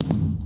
firework3.mp3